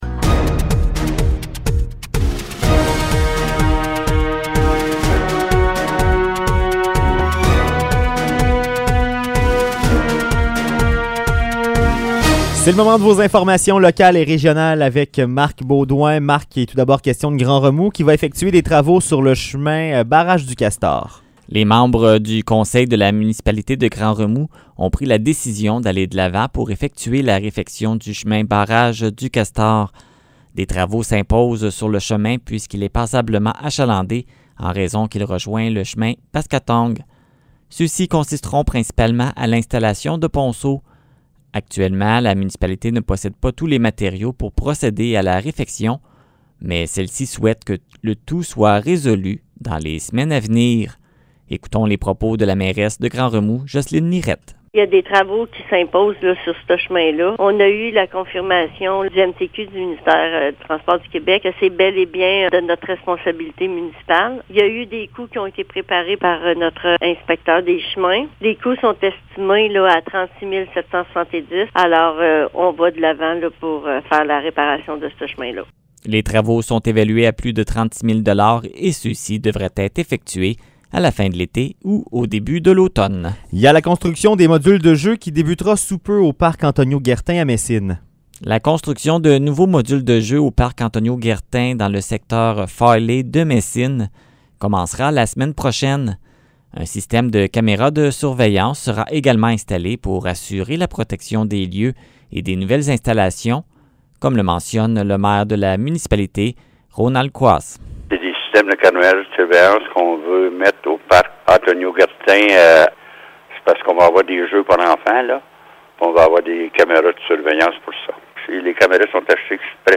Nouvelles locales - 23 juillet 2021 - 16 h